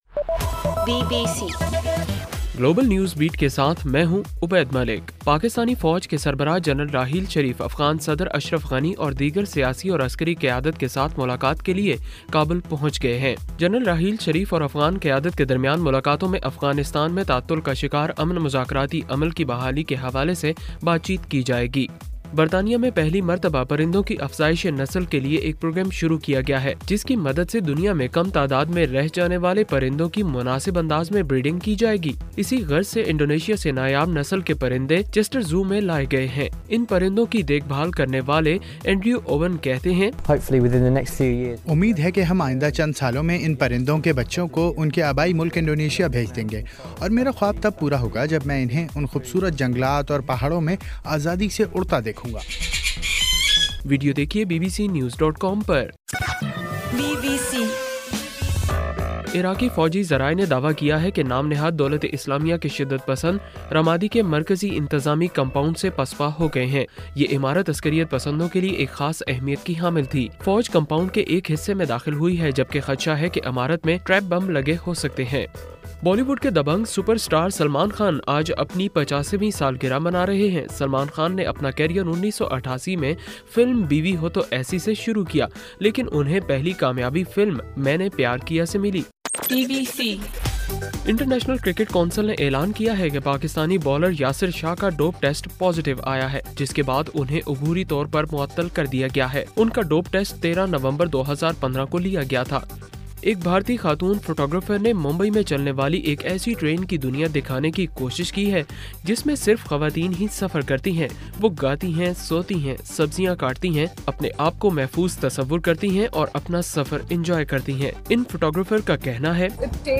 دسمبر 27: رات 8 بجے کا گلوبل نیوز بیٹ بُلیٹن